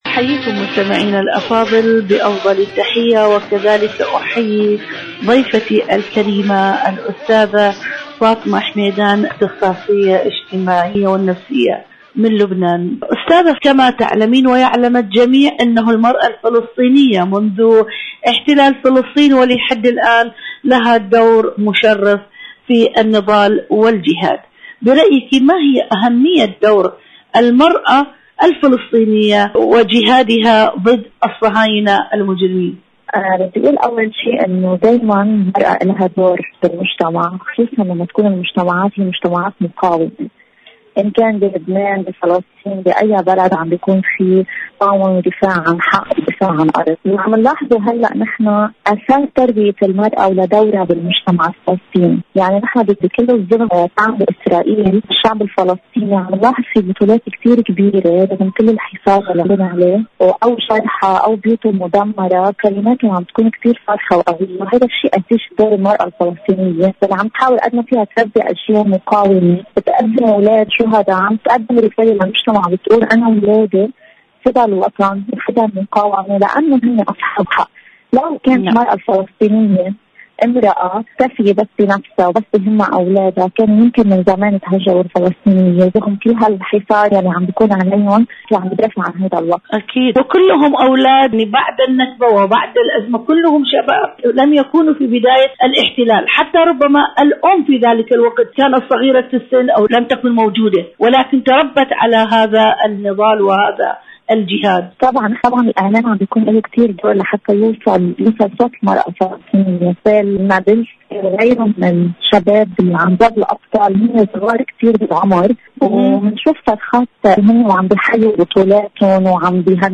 إذاعة طهران-عالم المرأة: مقابلة إذاعية